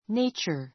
nature 小 A2 néitʃə r ネ イチャ 名詞 ❶ 自然 , 自然界 関連語 「自然の」は natural . the forces of nature the forces of nature 自然の力 ⦣ 風雨・日光・地震 じしん など.